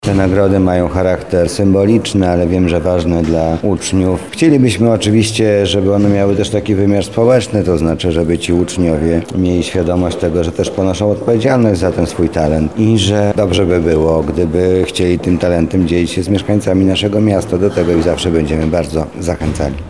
Swoje pochwały dla uczniów i nauczycieli złożył również Zastępca Prezydenta ds. Oświaty i Wychowania, Mariusz Banach.